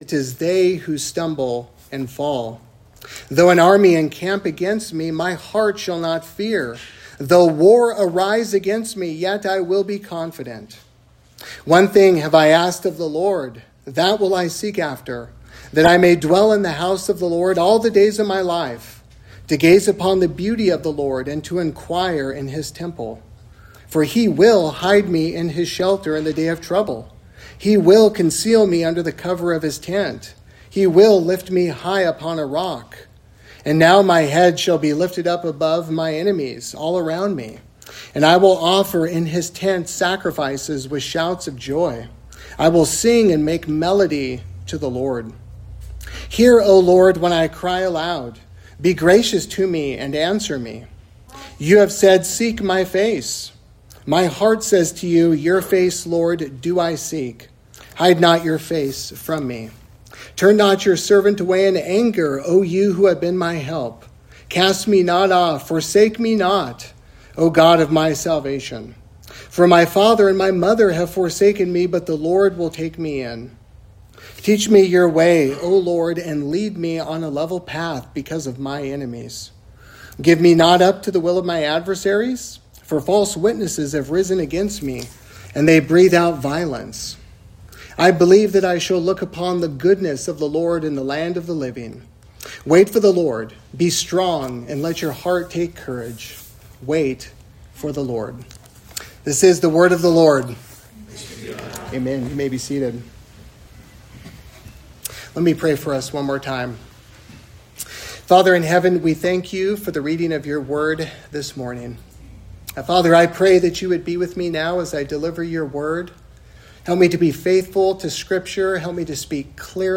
3Rivers Presbyterian Church - Sermons